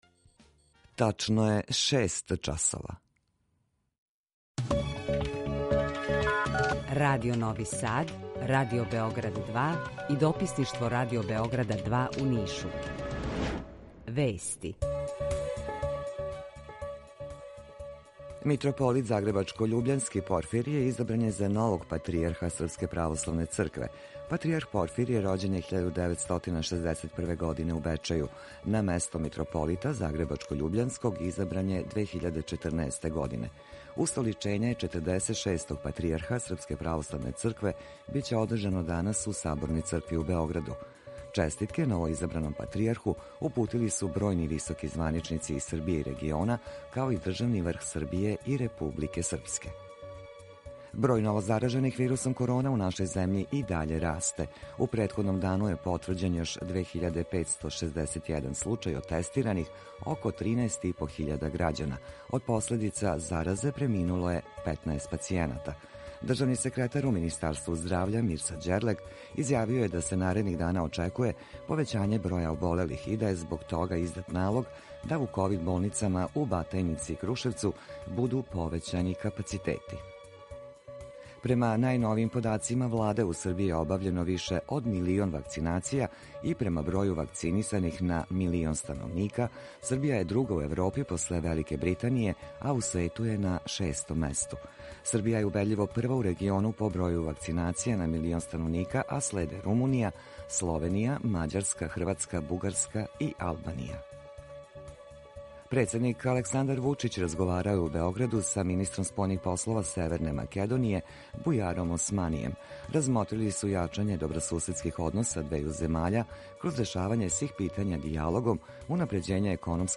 Укључење Радија Бањалукe
Jутарњи програм заједнички реализују Радио Београд 2, Радио Нови Сад и дописништво Радио Београда из Ниша. Cлушаоци могу да чују најновије информације из сва три града, а петком и информације из Бања Луке од колега из Радио Републике Српске.
У два сата, ту је и добра музика, другачија у односу на остале радио-станице.